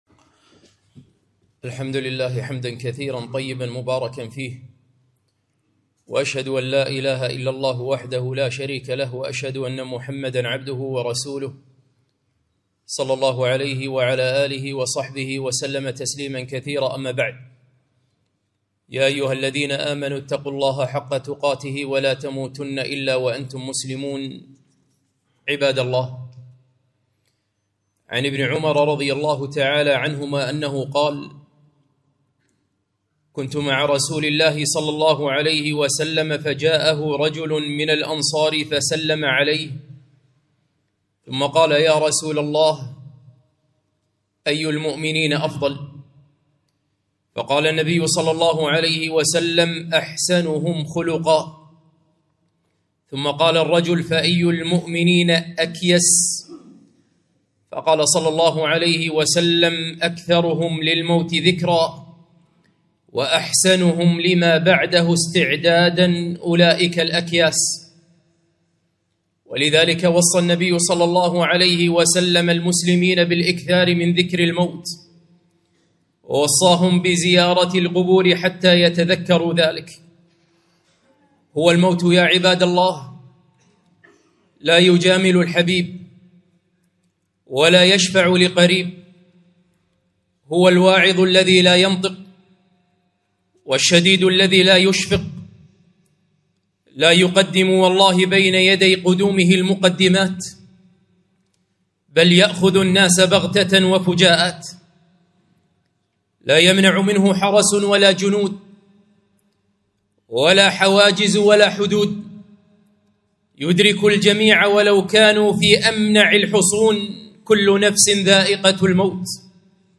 خطبة - هل تذكرناه